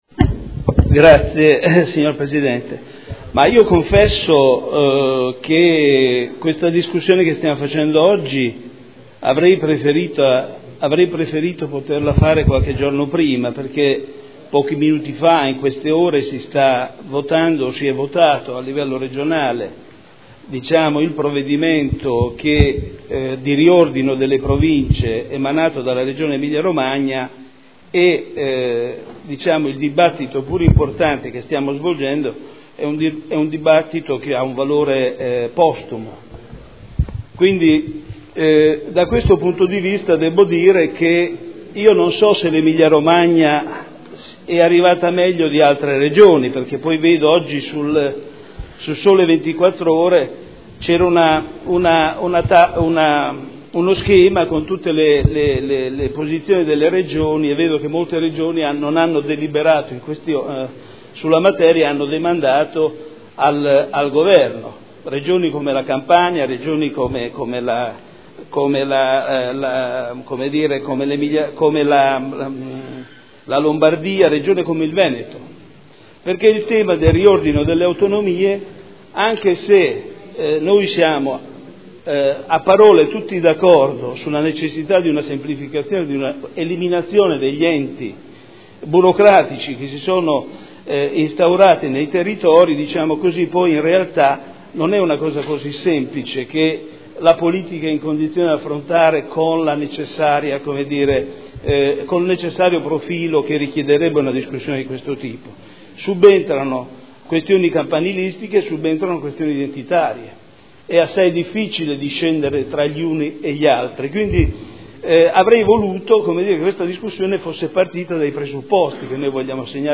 Seduta del 22/10/2012.